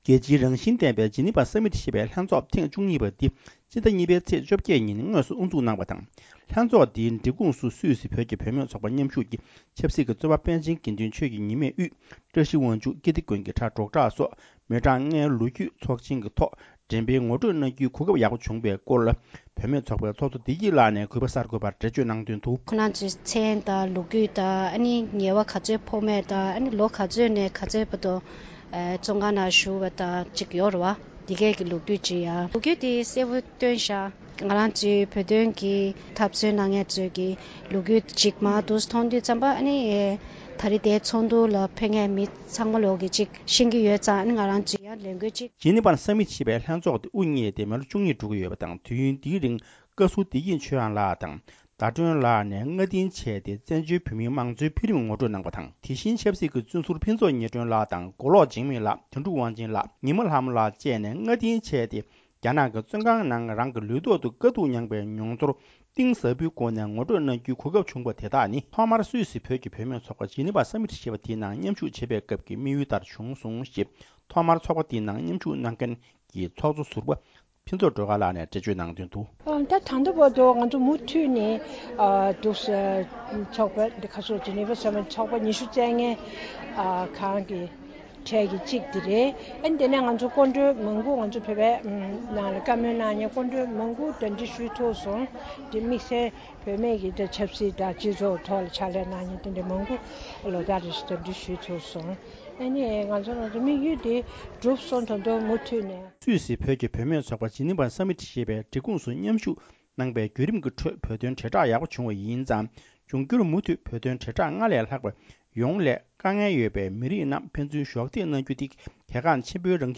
སུད་སི་ནས་སྙན་སྒྲོན་ཞུས་པར་གསན་རོགས་ཞུ།།